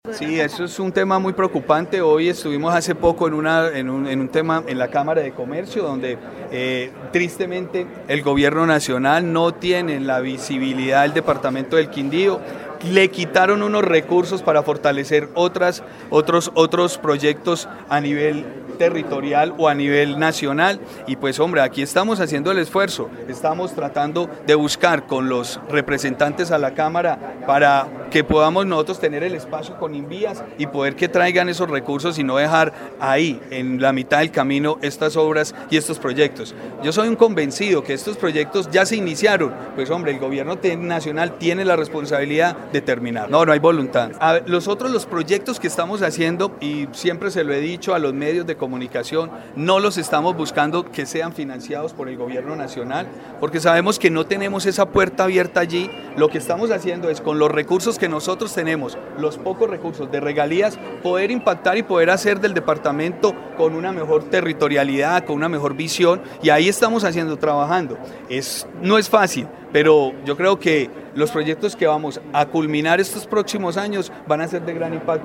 Gobernador del Quindío